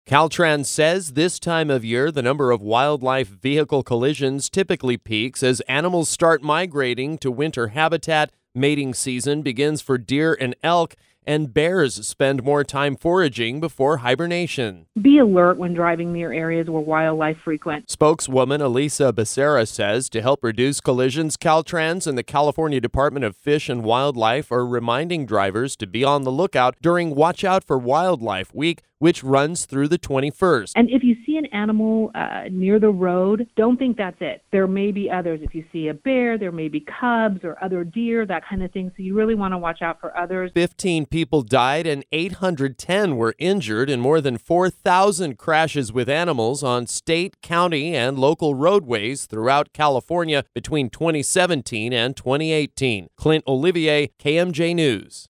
CO-WILDLIFE-CRASHES.mp3